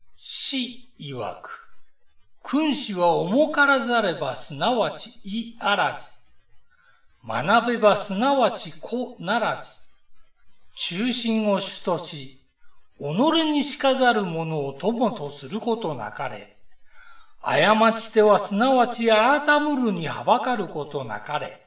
下の ＜朗読音声＞ をクリック又はタップすると、朗読音声が流れます。